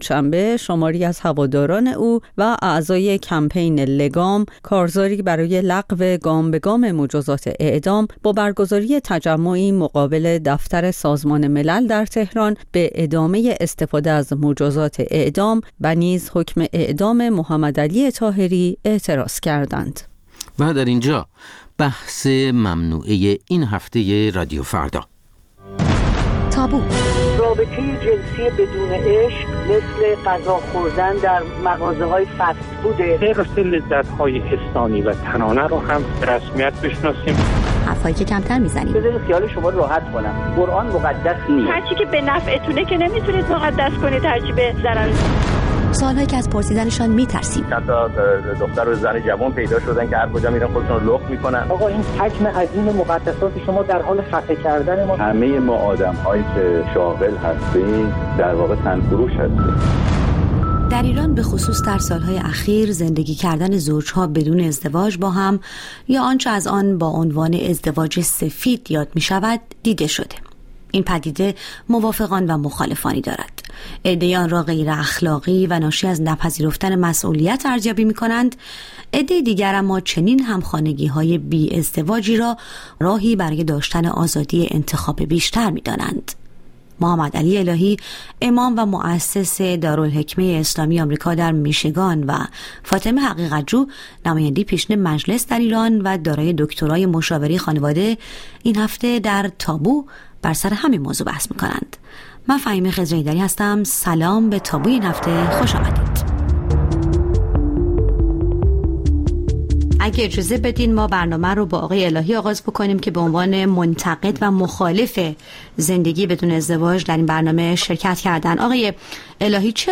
با دو‌ مهمانِ برنامه درباره‌ی موضوعاتی که اغلب کمتر درباره‌شان بحث و گفت‌وگو کرده‌ایم به مناظره می‌نشیند. موضوعاتی که کمتر از آن سخن می‌گوییم یا گاه حتی ممکن است از طرح کردن‌شان هراس داشته باشیم.